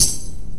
normal-slidertick.wav